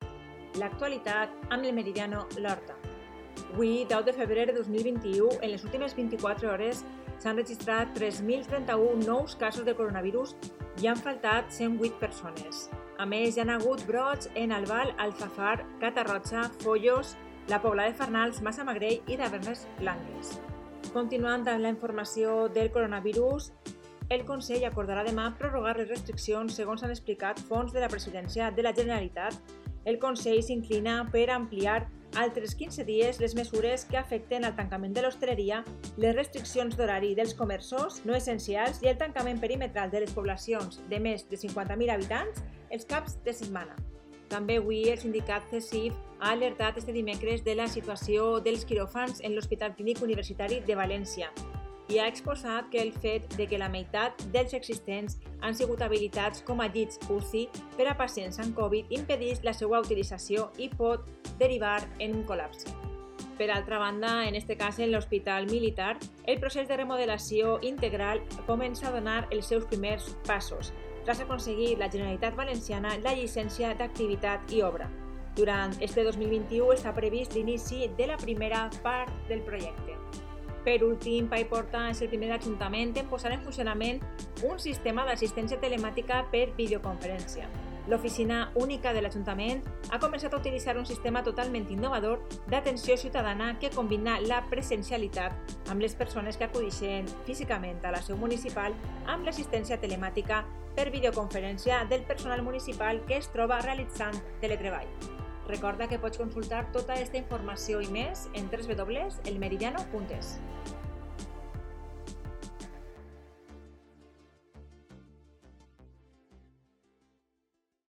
Informativo 10/2/21: